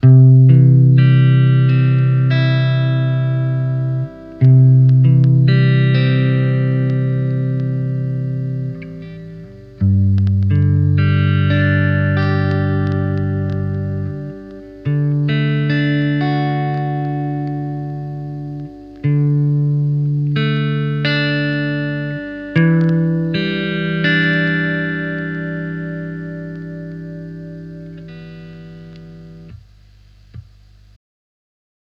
Гитарный треск.
Сегодня подрубил гитару и появился треск при дергании струн. Гитара дешевенькая китайская-не экранированная.
Схема коммутации: ламповый гитарный предусилитель-> Комбик-> снятие конденсаторным микрофоном-> микрофонный предусилитель.